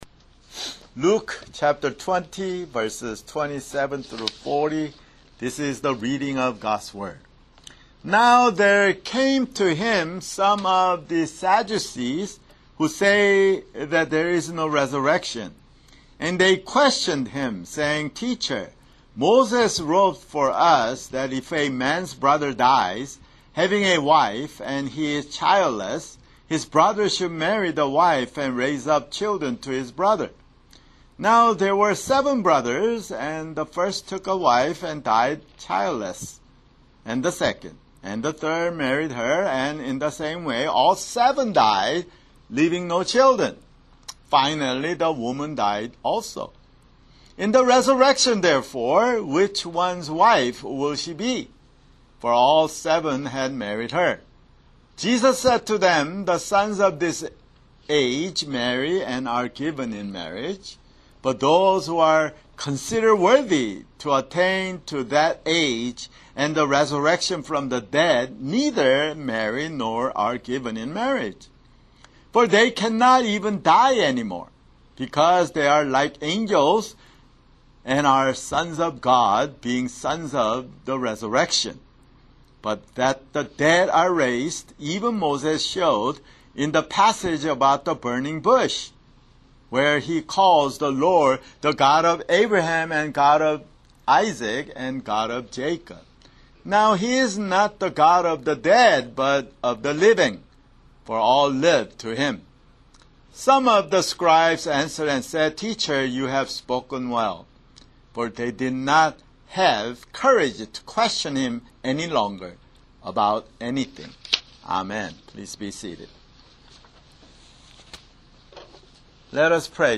[Sermon] Luke (133)